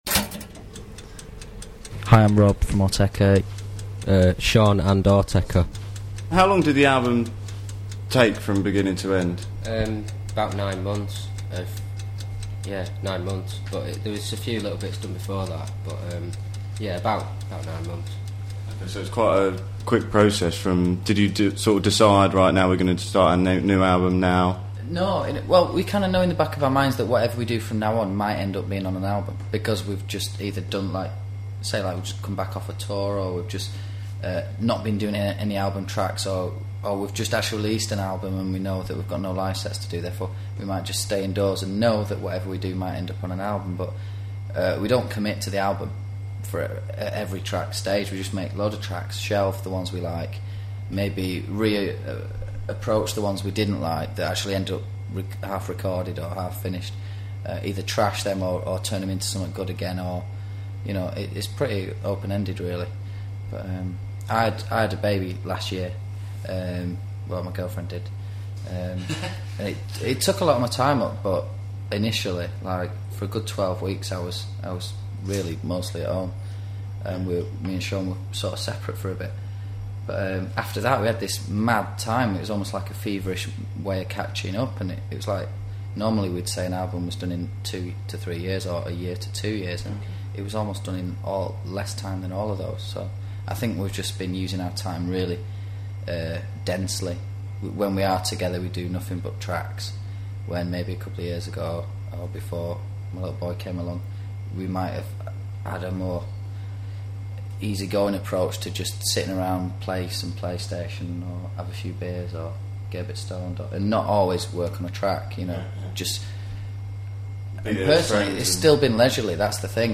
Autechre___2005_07_XX_Toazted.com_interview___1.mp3